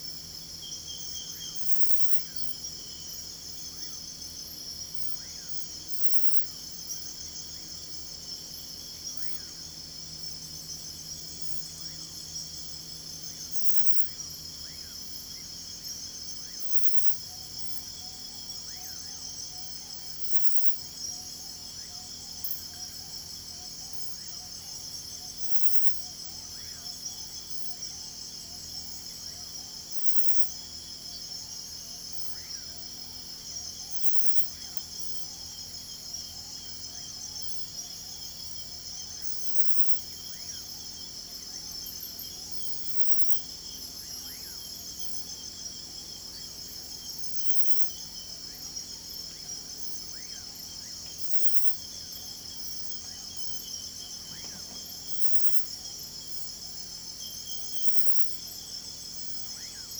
Finals-Data-Ultrasound